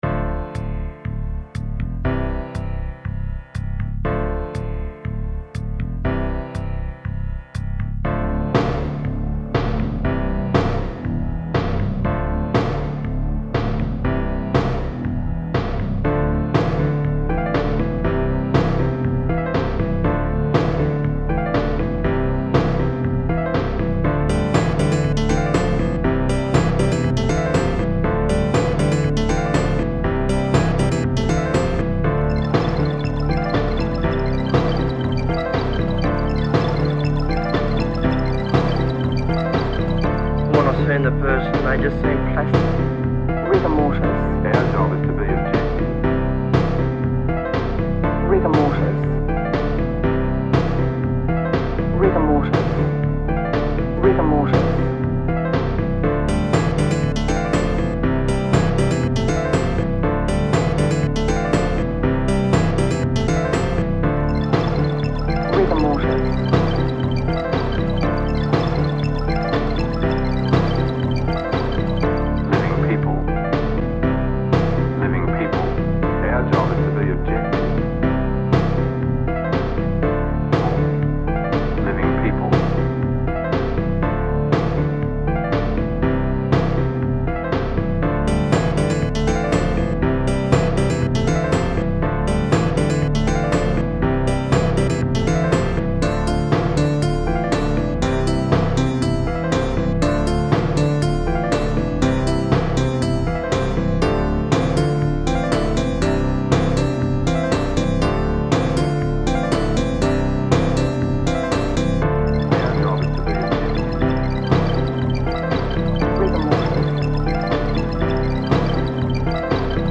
The instruments are all from a couple of Yamaha and AKAI samplers, I can’t remember which.